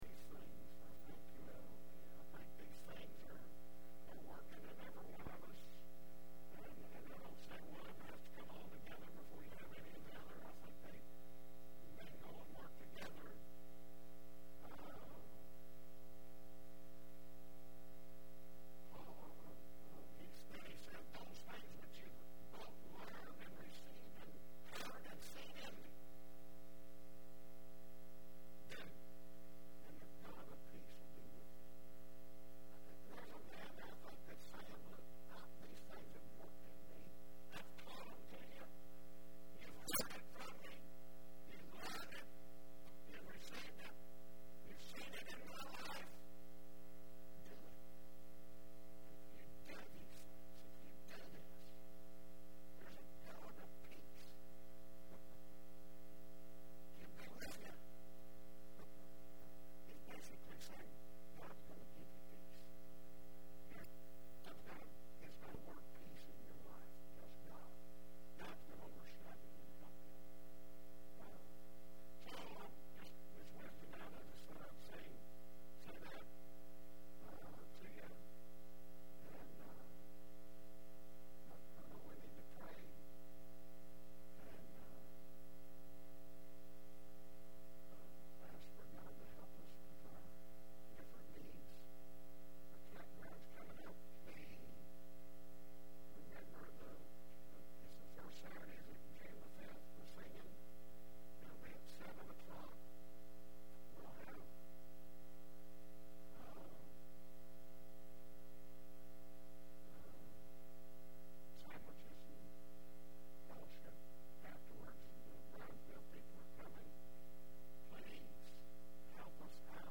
5/12/10 Wednesday Service
2010 Media Evening Service